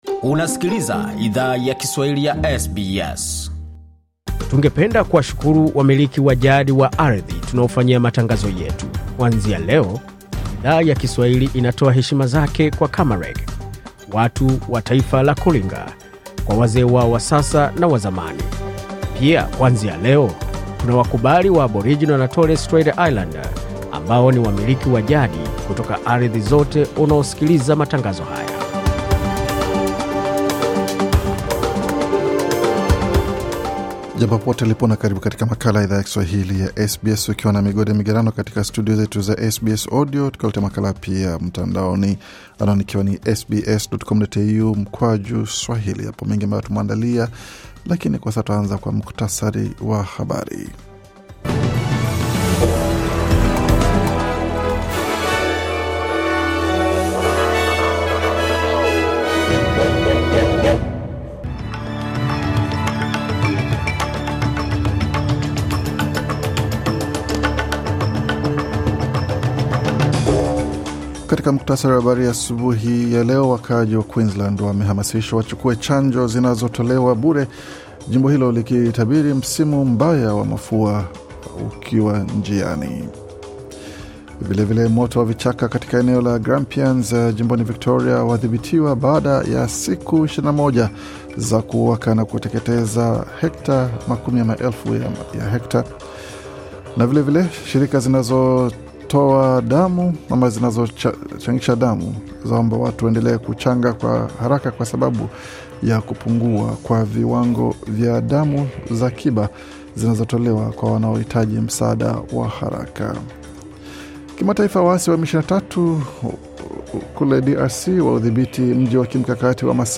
Taarifa ya Habari 7 Januari 2025